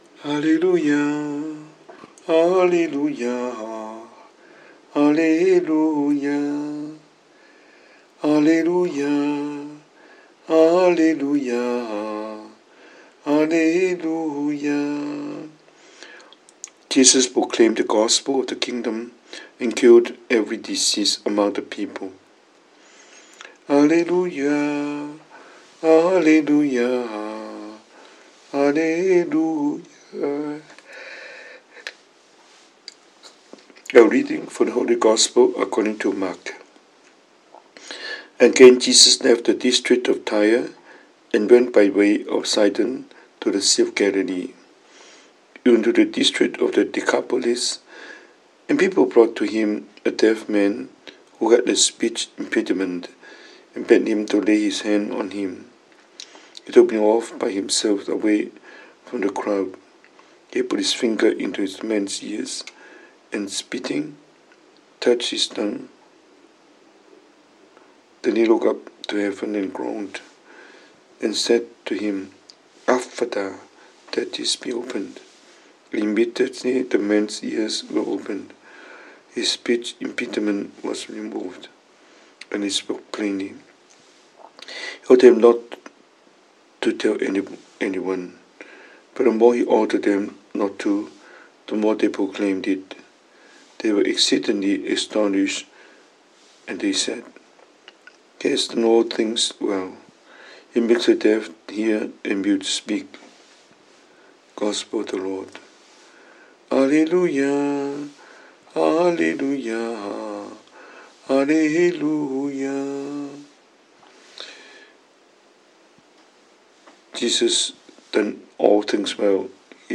神父講道